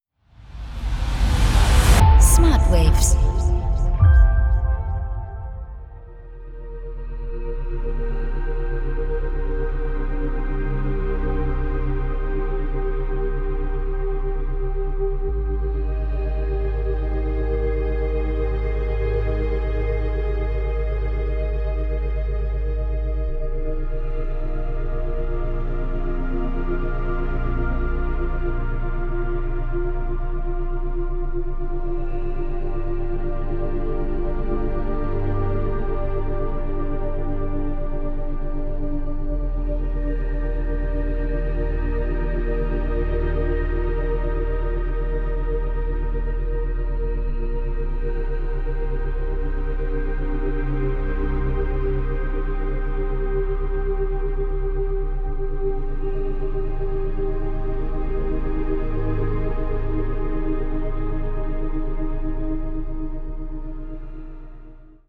0,5-4 Hertz Delta Wellen Frequenzen